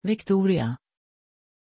Pronunciation of Victoriah
pronunciation-victoriah-sv.mp3